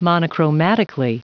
Prononciation du mot monochromatically en anglais (fichier audio)
Prononciation du mot : monochromatically